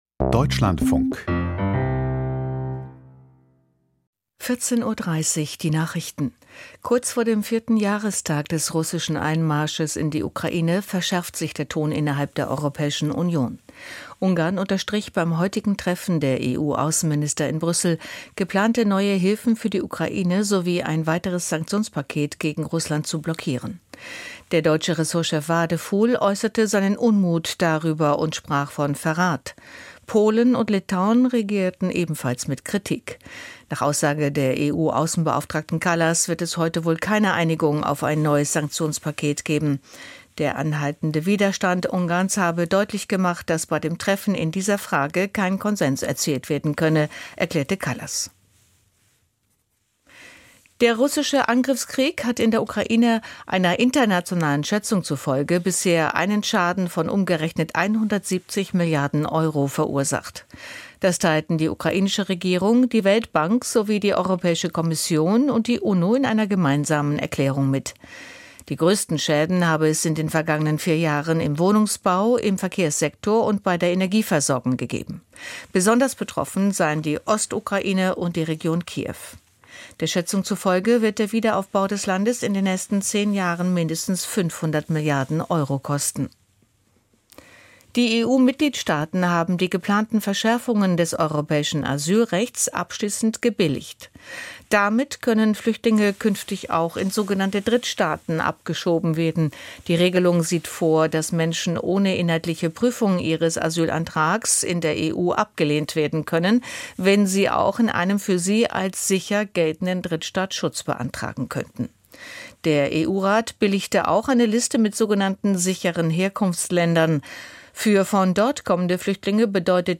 Die wichtigsten Nachrichten aus Deutschland und der Welt.